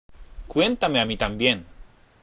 ＜発音と日本語＞
（クエンタメ　アミタンビエン）